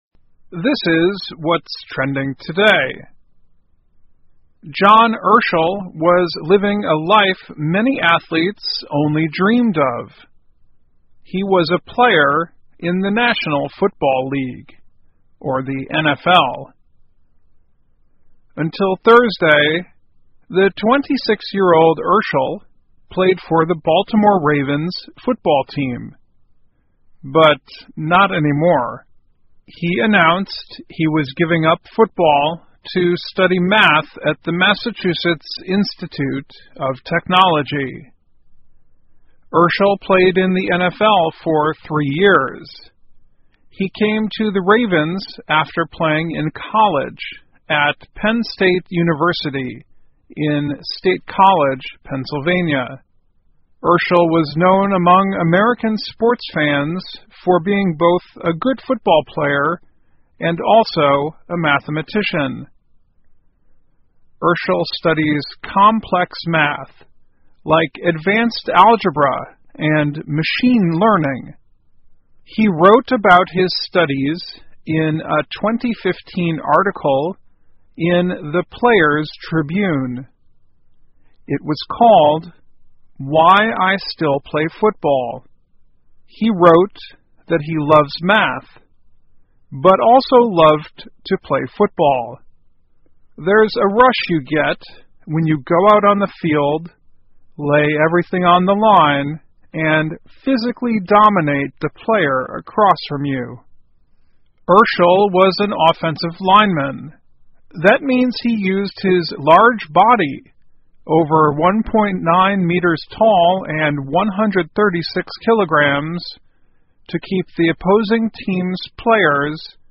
VOA慢速英语--美式足球运动员离开NFL学习数学 听力文件下载—在线英语听力室